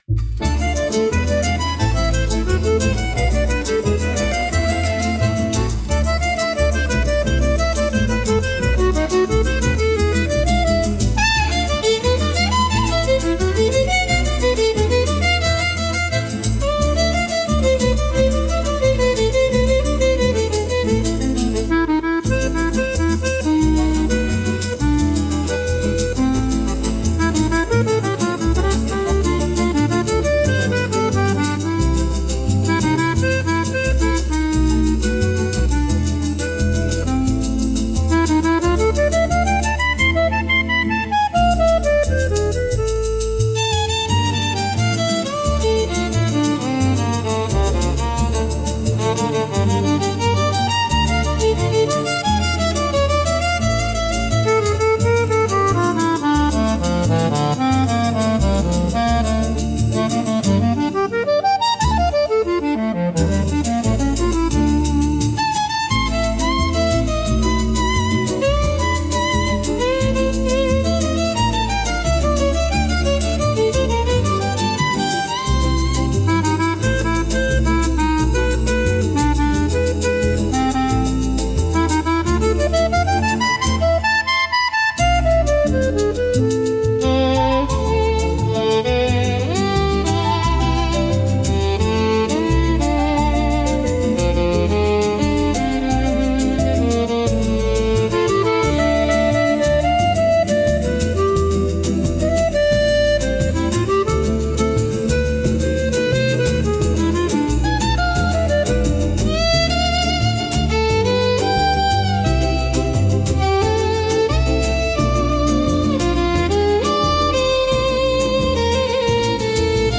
música e arranjo: IA) (Instrumental)